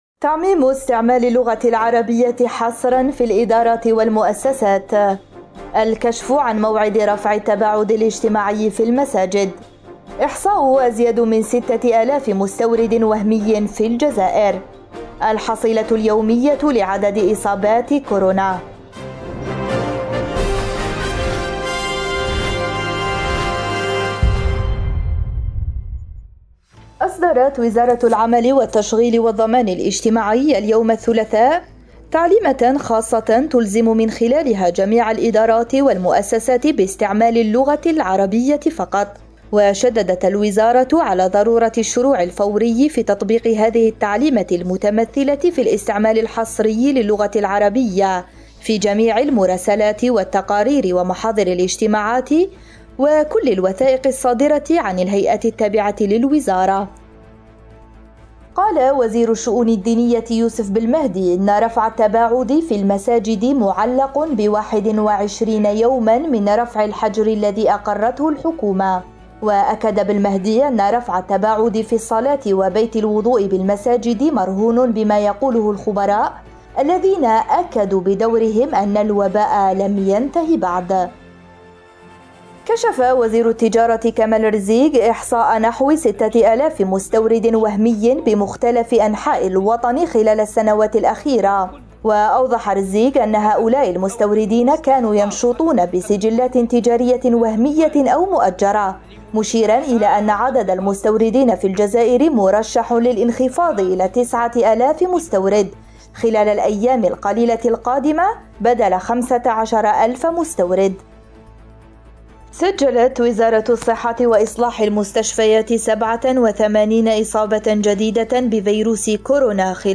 النشرة اليومية: اللغة العربية تزيح الفرنسية من هيئات حكومية – أوراس
النشرة الرقميةفي دقيقتين